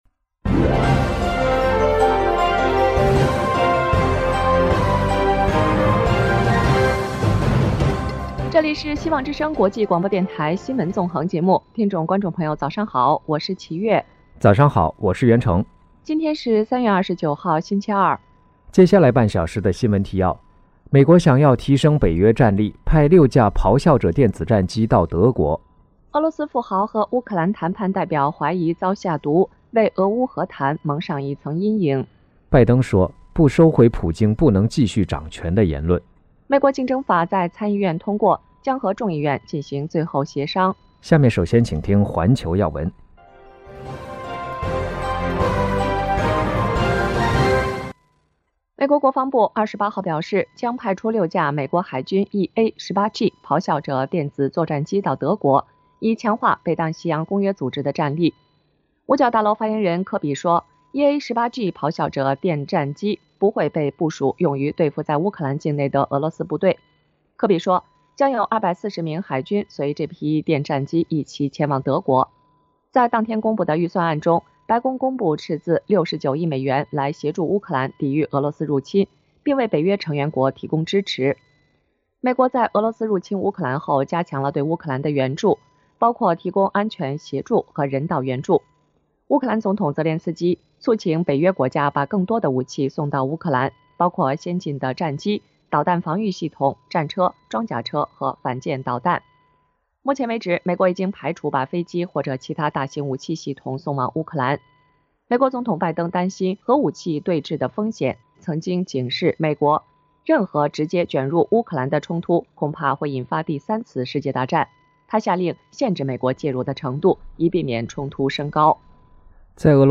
拜登2023预算 增款对抗中俄 加征富人税【晨间新闻】